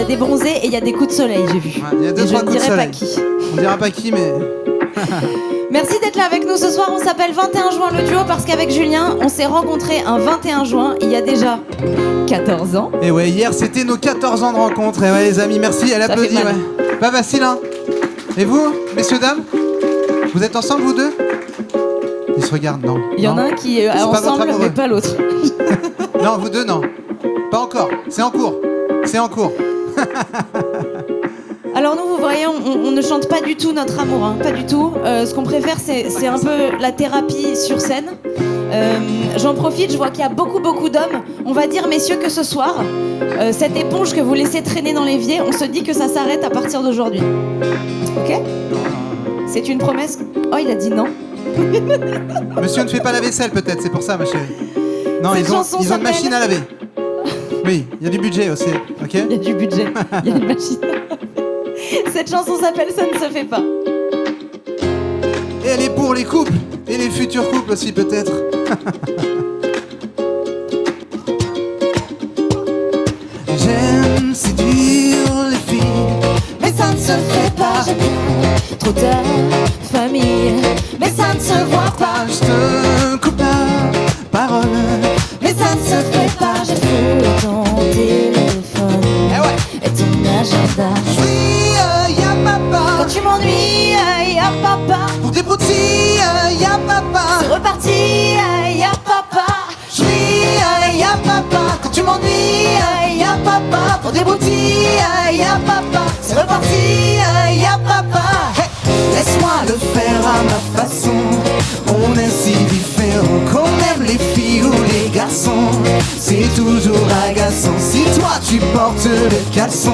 Revivez ce concert exclusif.